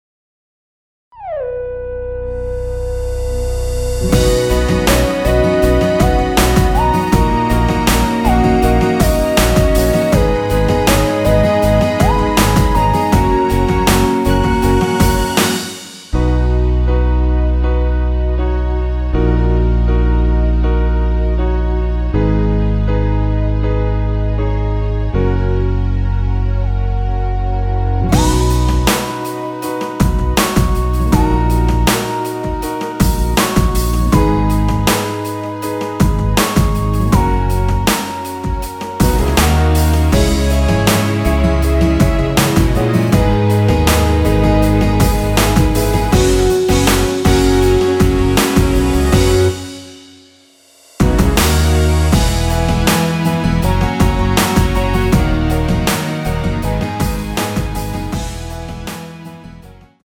원키에서(+2)올린 MR입니다.
앞부분30초, 뒷부분30초씩 편집해서 올려 드리고 있습니다.